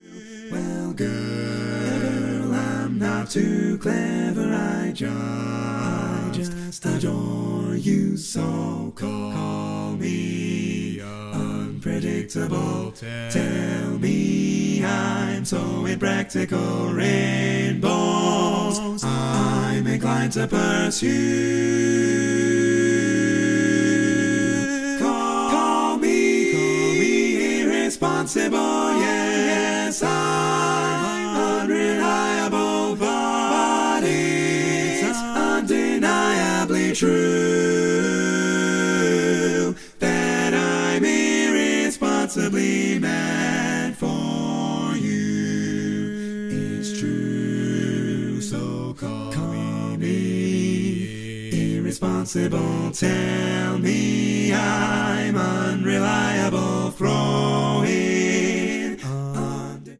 Male
Without intro